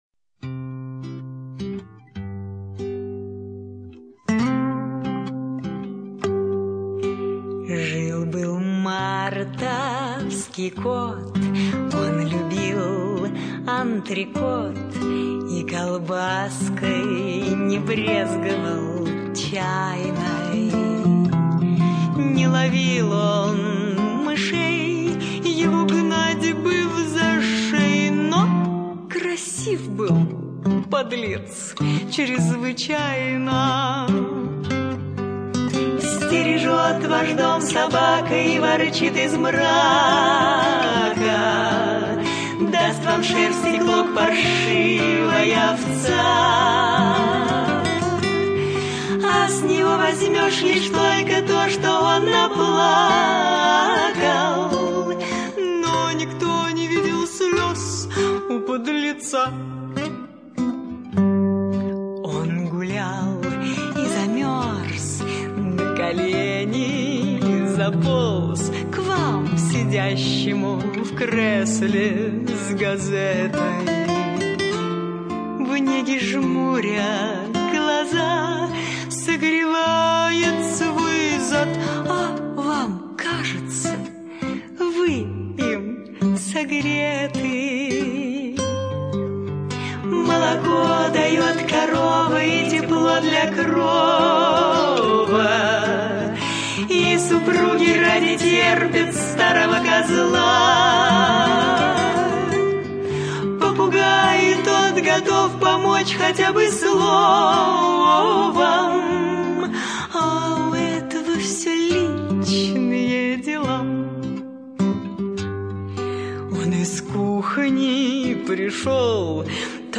"Мартовский кот"  - детская авторская песенка